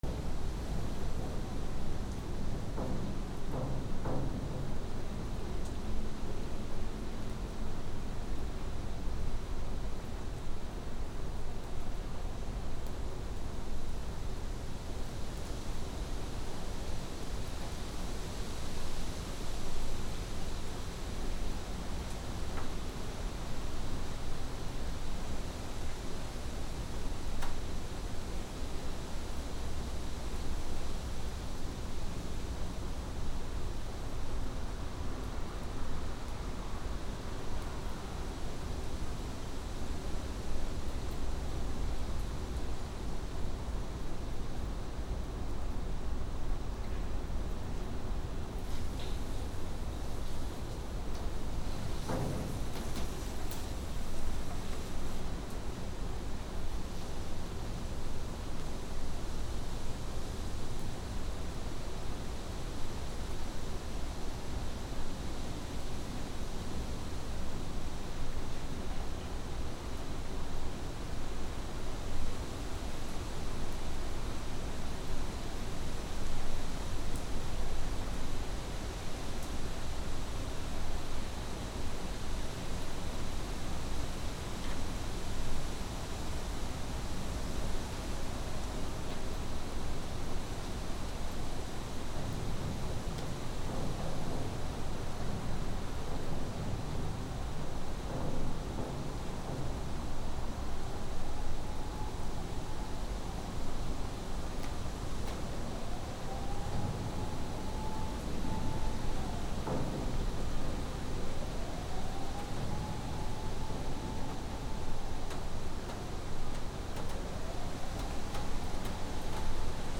/ A｜環境音(天候) / A-45 ｜台風 嵐
台風6
NT4 原音あり